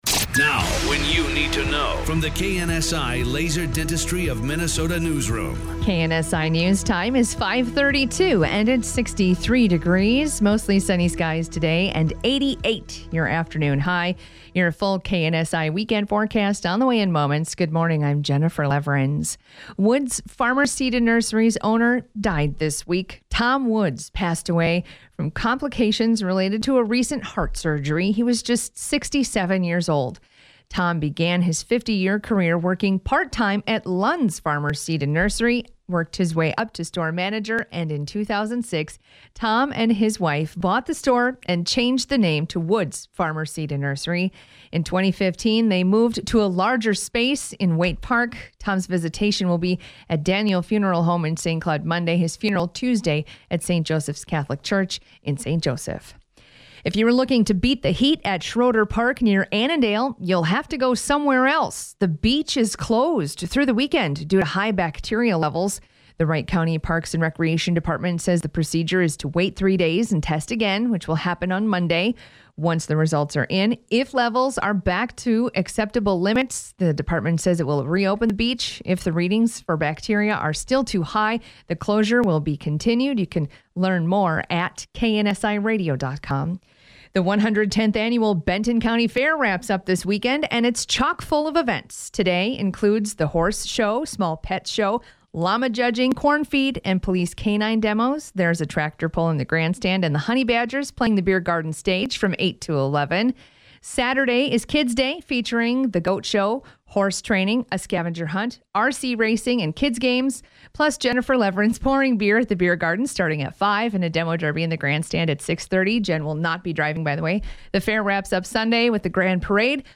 August 4th, 2023 5:30 a.m. Newscast
This newscast aired at 5:32 a.m. Friday, August 4th, 2023.